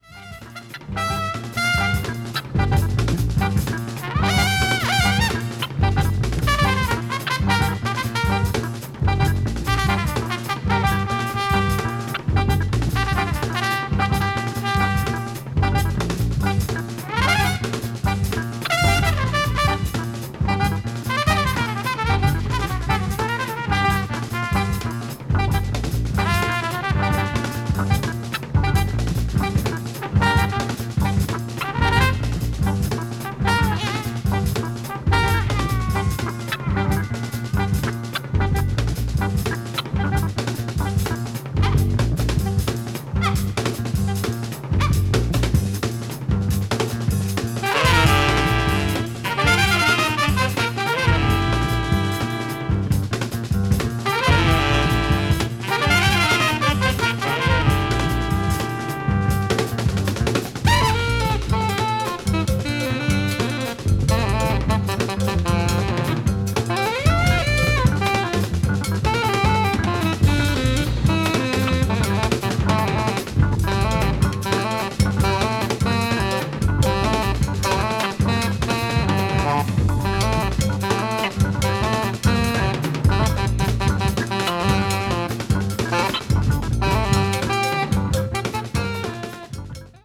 free/experimental jazz rooted in African traditional music